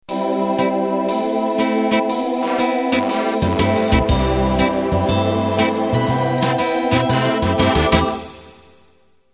Second of another pair of Euro-Pop Swing